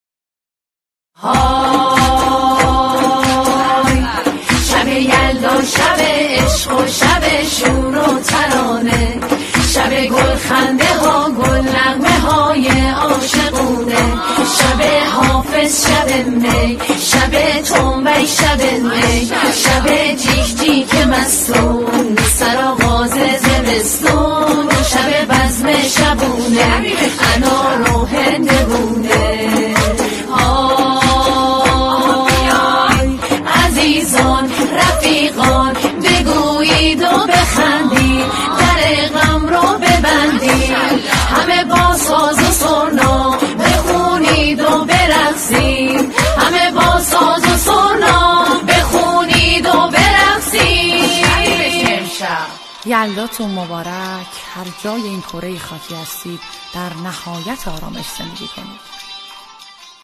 با تنبک